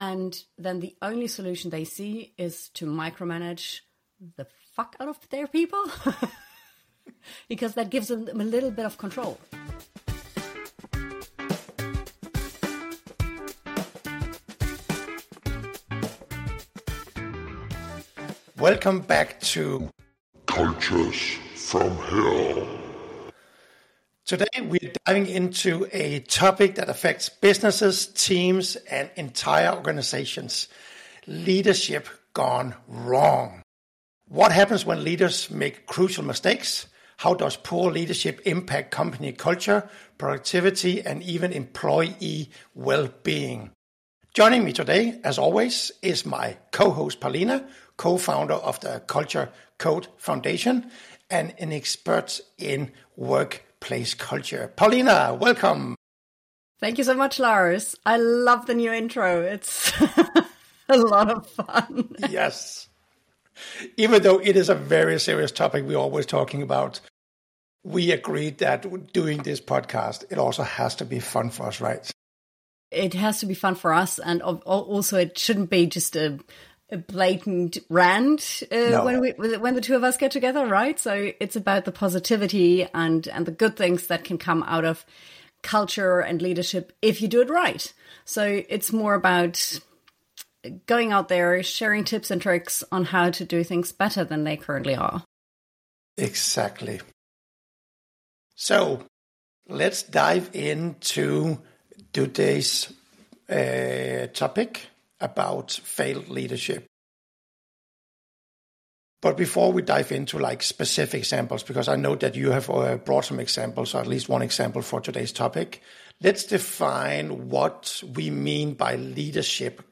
They discuss the common mistakes leaders make, the importance of proper training, and how poor leadership can negatively impact company culture and employee well-being. The conversation highlights the need for self-reflection, the identification of red flags in leadership, and the significance of trust and communication.